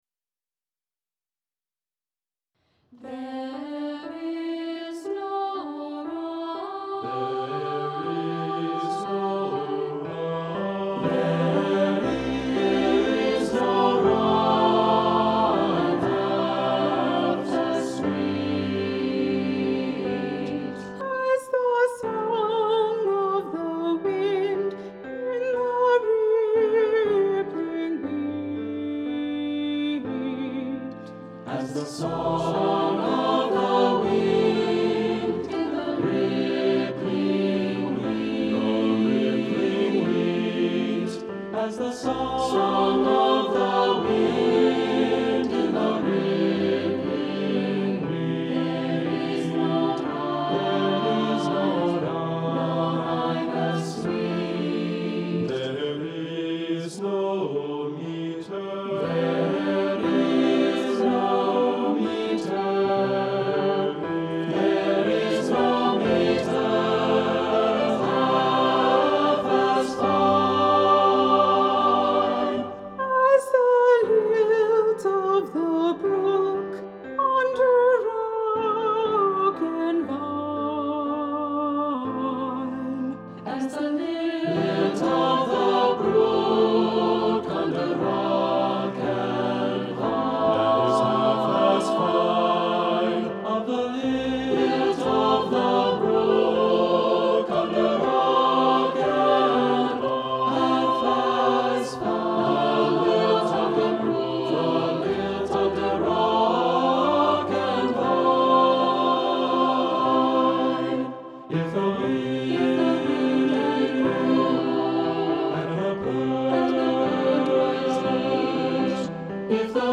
This is a work performed by the Stony Creek Singers during our June 2023 concert at the Stony Creek Congregational Church.